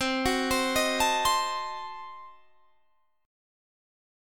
Cdim7 Chord
Listen to Cdim7 strummed